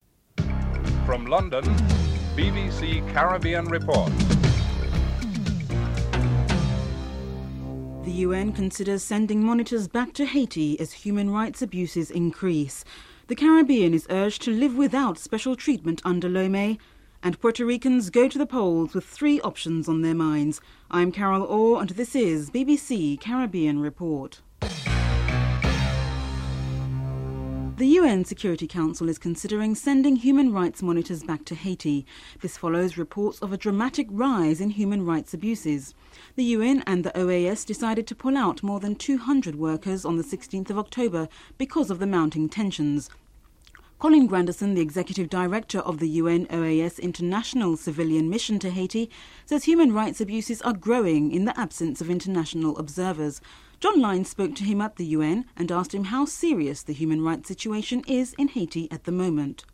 dc.formatStereo 192 bit rate MP3;44,100 Mega bits;16 biten_US
dc.typeRecording, oralen_US